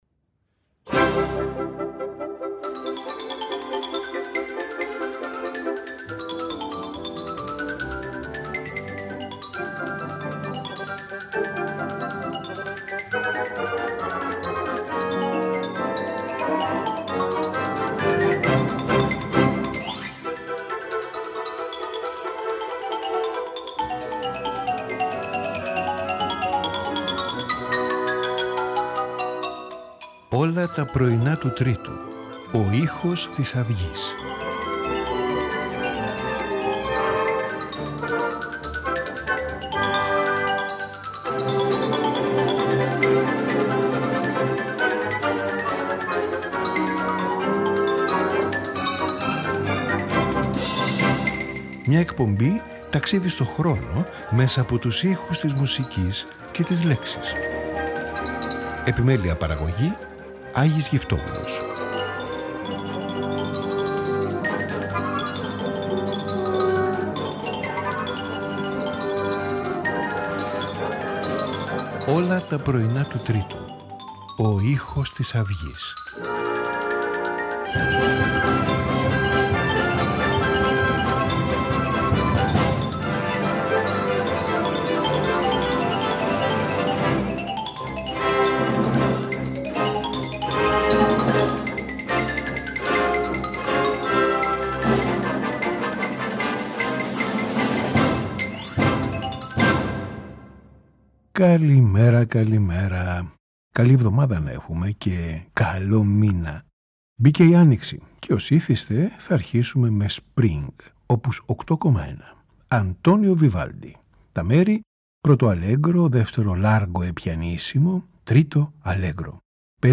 for violin and piano